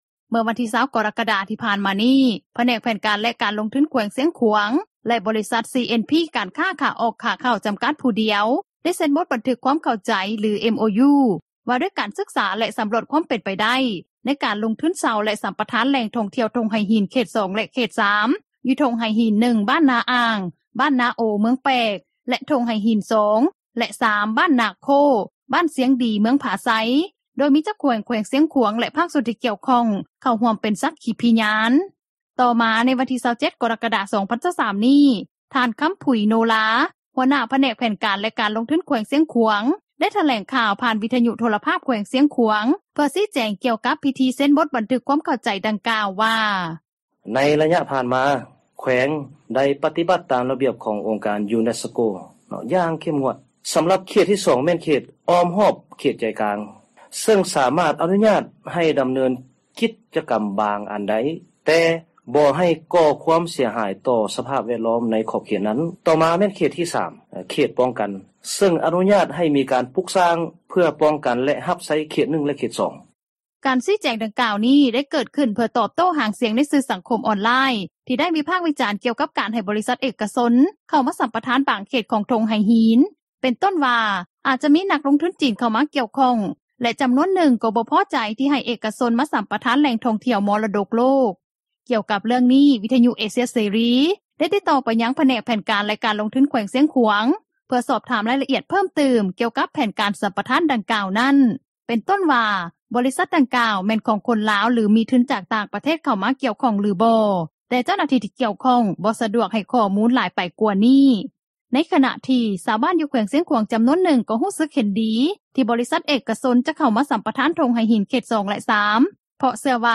ດັ່ງຊາວບ້ານ ທ່ານນຶ່ງ ກ່າວຕໍ່ວິທຍຸ ເອເຊັຽ ເສຣີ ໃນວັນທີ 28 ກໍຣະກະດາ ນີ້ວ່າ: